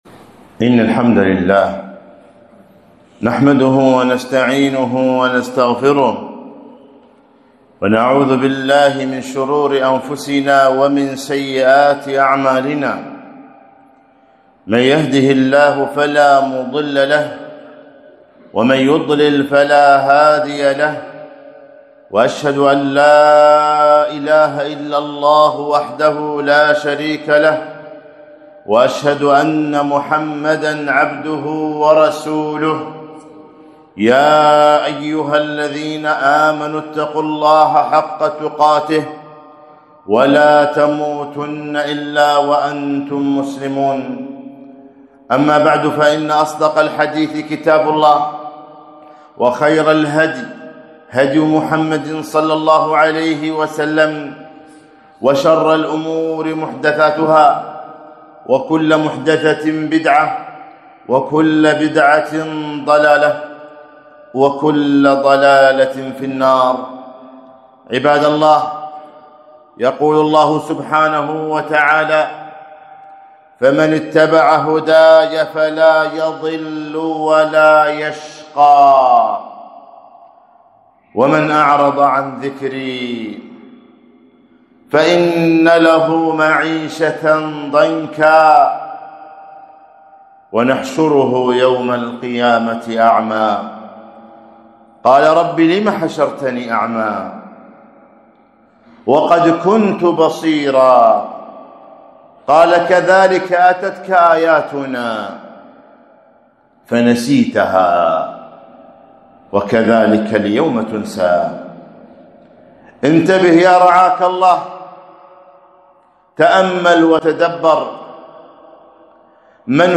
خطبة - فمن اتبع هداي فلا يضل ولا يشقى